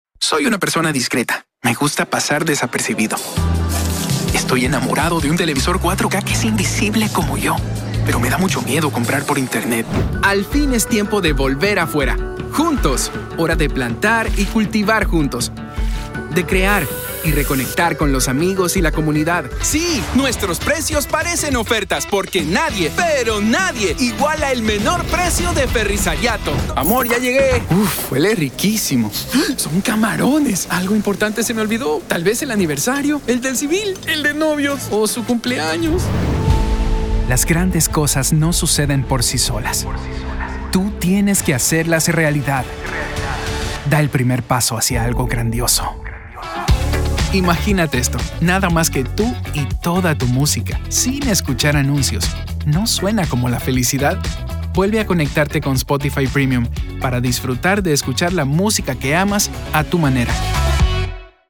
Espanhol (latino-americano)
Espanhol (mexicano)
Espanhol (venezuelano)
Conversacional
Jovem
Crível